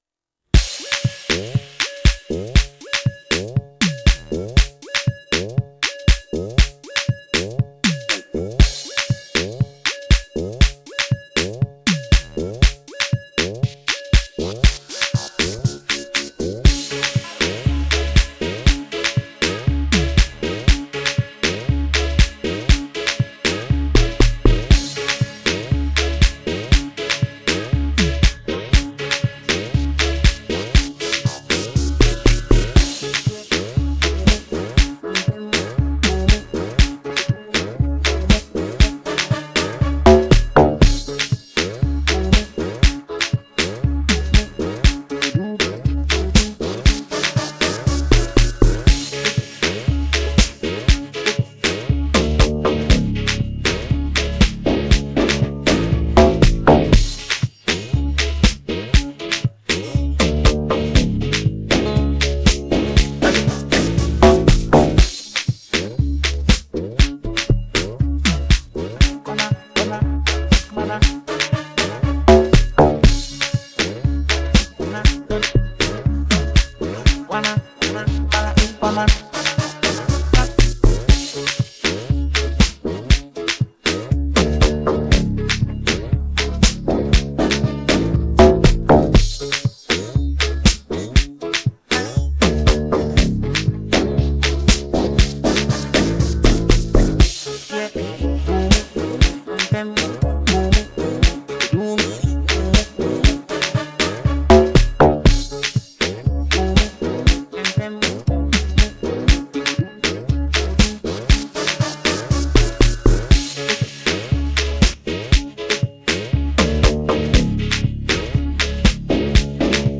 GhanapianoHiplife